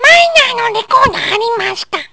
音声(wav） 　　←コンピューター合成です（笑）